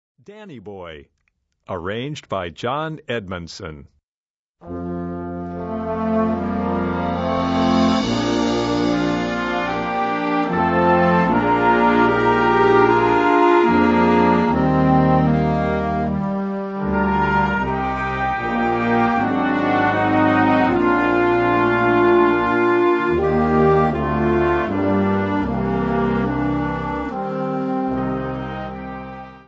Catégorie Harmonie/Fanfare/Brass-band
Sous-catégorie Folklore international
Instrumentation Ha (orchestre d'harmonie)